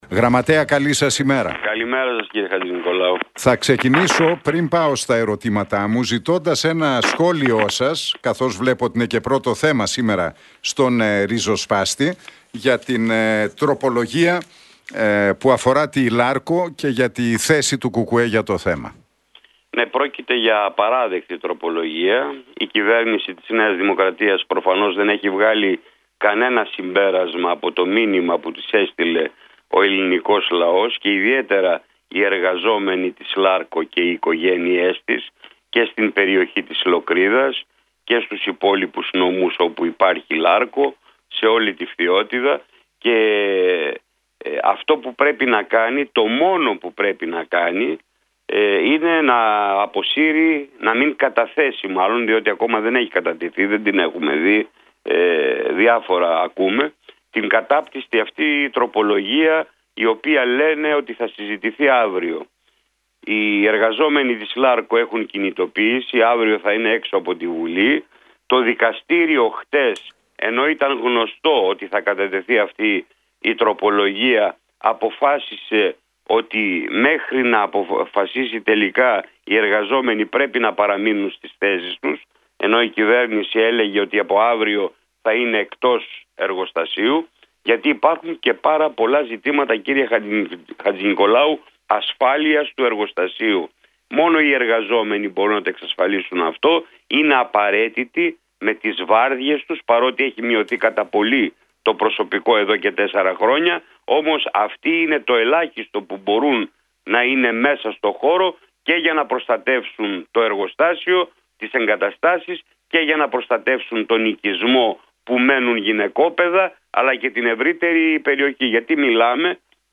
Στις ευρωεκλογές σημειώθηκε μια νέα σημαντική άνοδος του ΚΚΕ που επιβεβαιώνει τις θετικές διεργασίες στην κοινωνία, τόνισε ο ΓΓ της ΚΕ του Κόμματος, Δημήτρης Κουτσούμπας, σε συνέντευξη που παραχώρησε στον RealFm 97,8 και την εκπομπή του Νίκου Χατζηνικολάου.